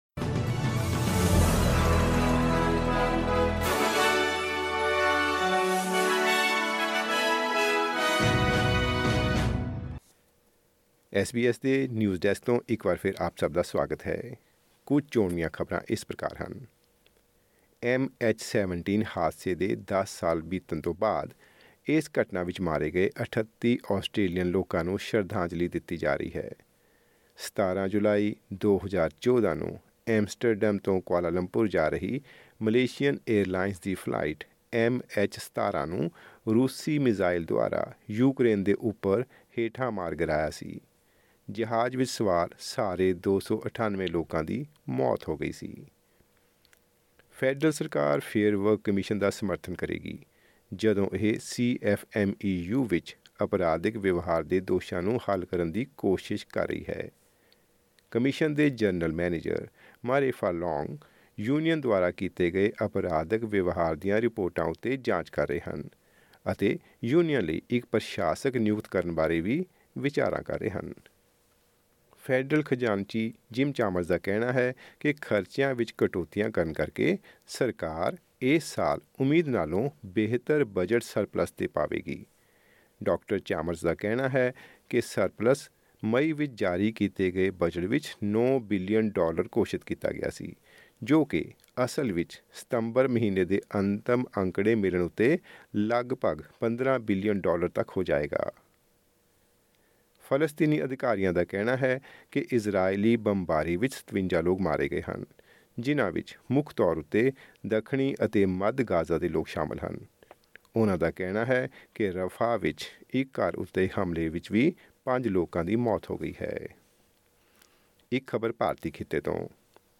ਐਸ ਬੀ ਐਸ ਪੰਜਾਬੀ ਤੋਂ ਆਸਟ੍ਰੇਲੀਆ ਦੀਆਂ ਮੁੱਖ ਖ਼ਬਰਾਂ: 17 ਜੁਲਾਈ 2024